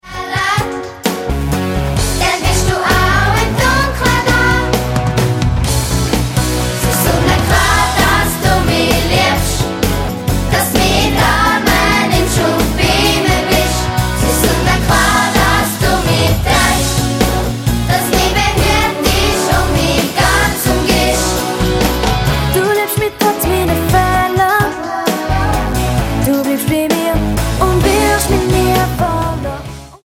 Mundart-Worshipsongs für Kids